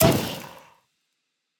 Minecraft Version Minecraft Version snapshot Latest Release | Latest Snapshot snapshot / assets / minecraft / sounds / mob / pufferfish / sting1.ogg Compare With Compare With Latest Release | Latest Snapshot
sting1.ogg